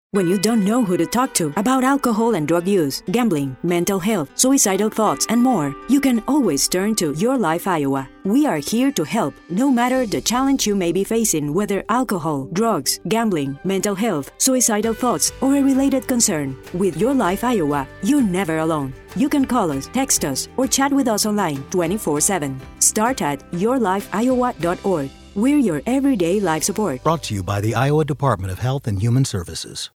:30 Radio Spot | YLI Awareness (Female-3)